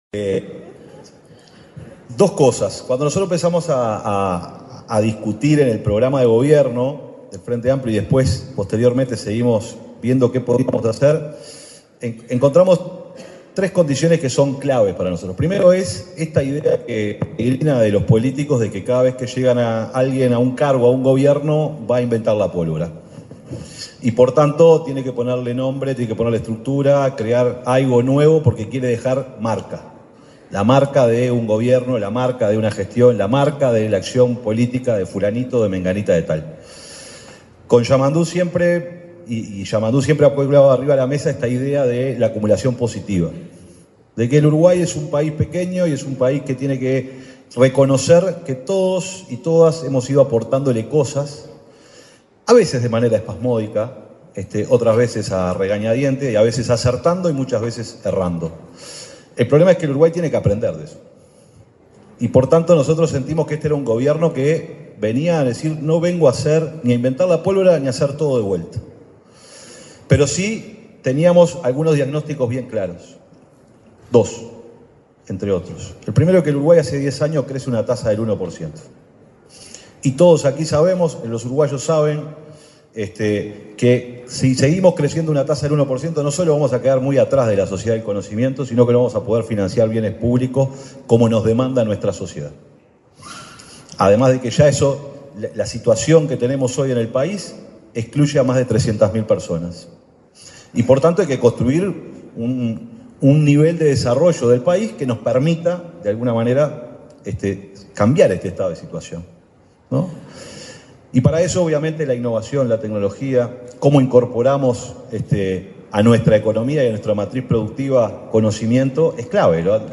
El secretario de la Presidencia, Alejandro Sánchez, participó en un panel de expertos, realizado durante el lanzamiento del programa Uruguay Innova.